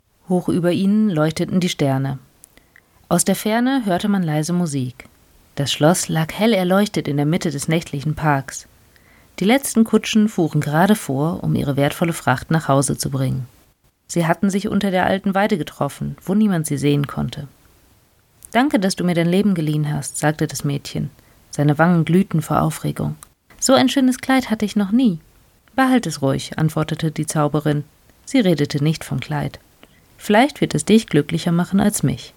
Natürlich gibt es noch eine Menge zu lernen – was das Vorlesen angeht genau so wie in Bezug auf die Technik-Technik.
PS: Falls ihr im Hintergrund ein gleichmäßiges Summen wahrnehmt – nein, das ist kein ultracooler Rausch-Effekt, sondern der schnurrende Kater auf dem Sofa hinter mir.